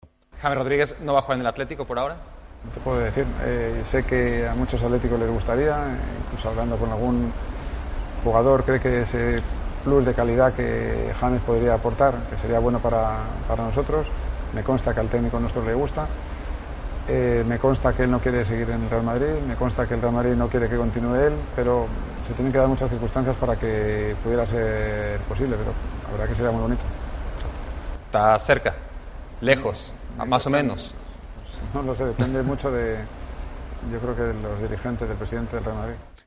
El consejero delegado del Atlético destacó, en una entrevista en ESPN, que la llegada del colombiano "depende mucho del presidente del Real Madrid".